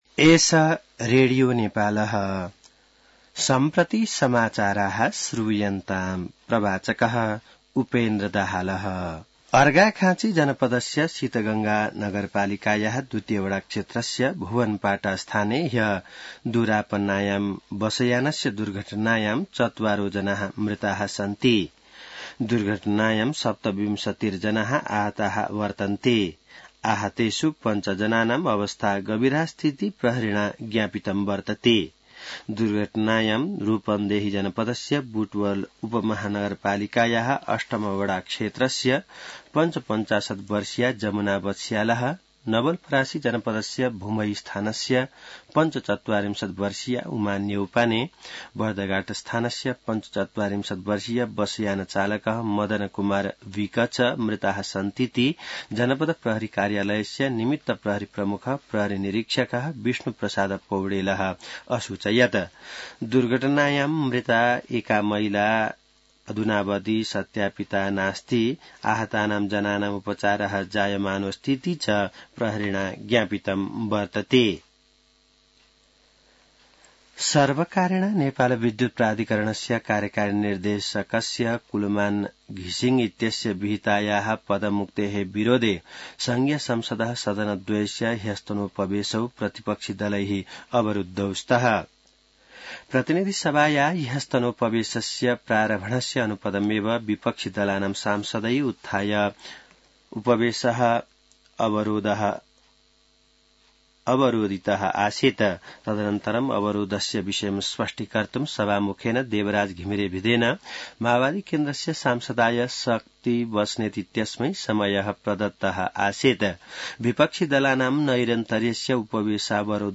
संस्कृत समाचार : १४ चैत , २०८१